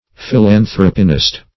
Search Result for " philanthropinist" : The Collaborative International Dictionary of English v.0.48: Philanthropinist \Phil`an*throp"i*nist\, n. An advocate of, or believer in, philanthropinism.